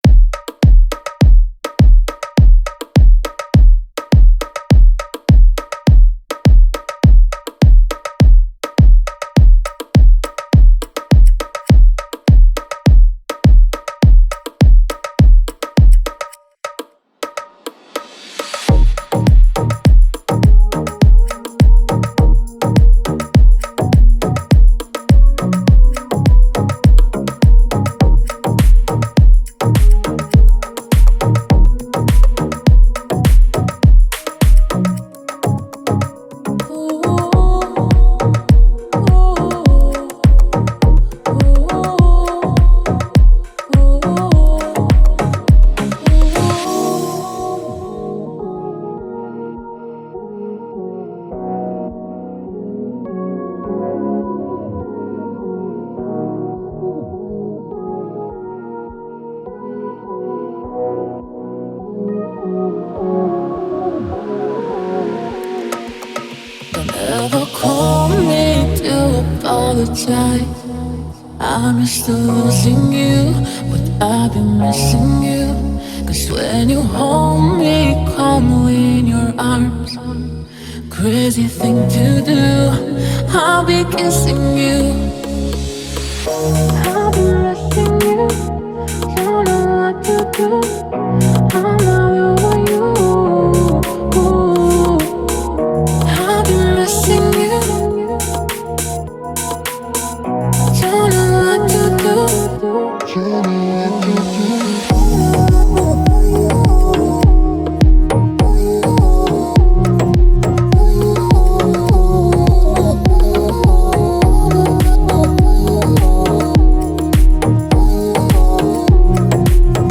это яркая танцевальная композиция в жанре EDM